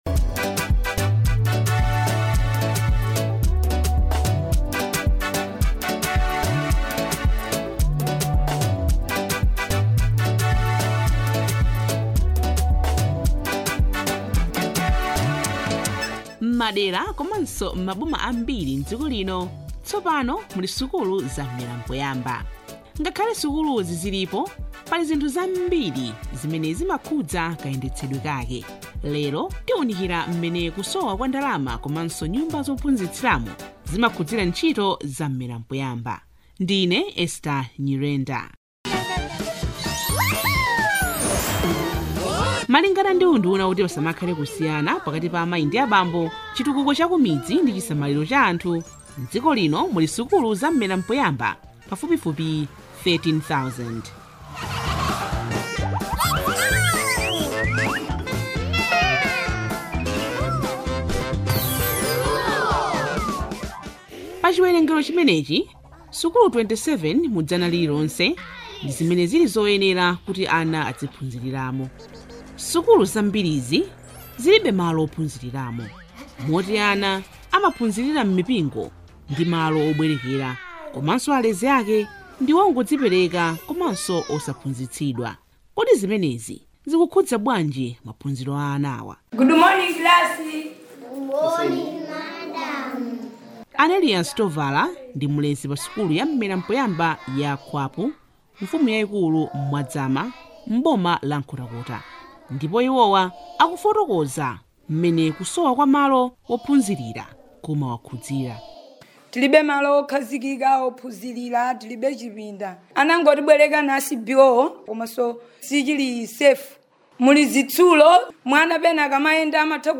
DOCUMENTARY ON ECONOMIC AND INFRASTRUCTURAL ON ECD - Part 1